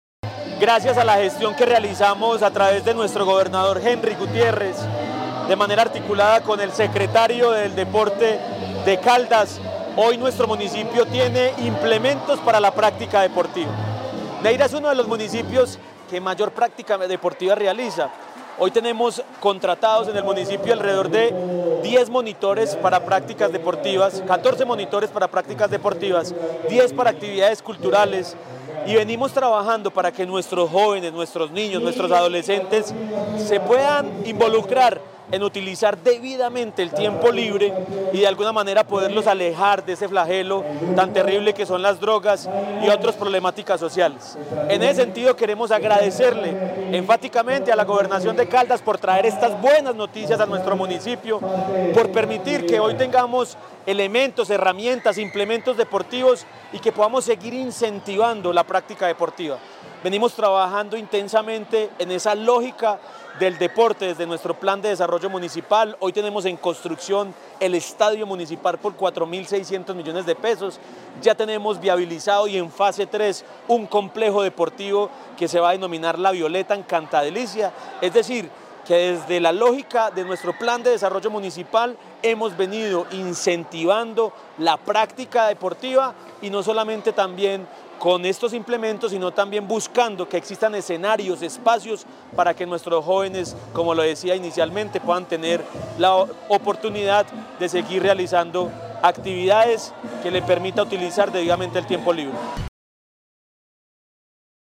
Jhon Jairo Castaño, alcalde de Neira.
jhon-jario-castano-alcalde-neira.mp3